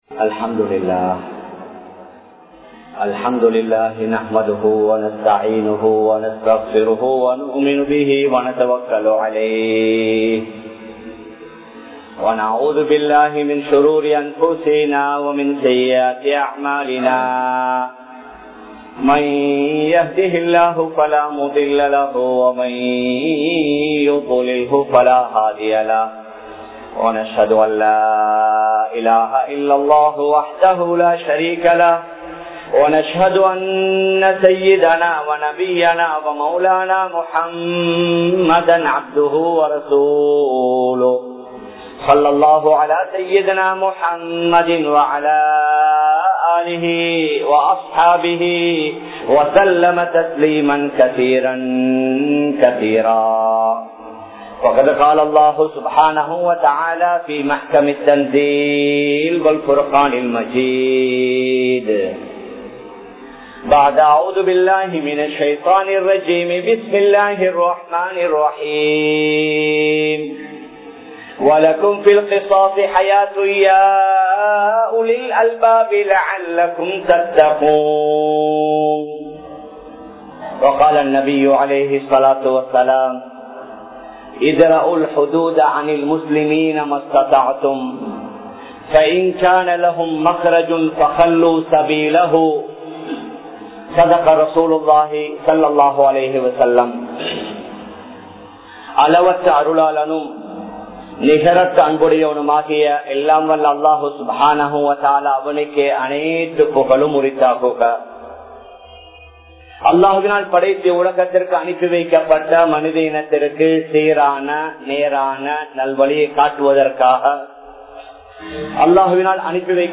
Vifachchaaramum Athatkaana Thandanaium (விபச்சாரமும் அதற்கான தண்டனையும்) | Audio Bayans | All Ceylon Muslim Youth Community | Addalaichenai
Colombo 03, Kollupitty Jumua Masjith